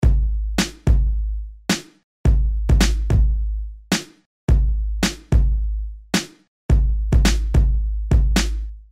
标签： 108 bpm Hip Hop Loops Drum Loops 1.50 MB wav Key : Unknown
声道立体声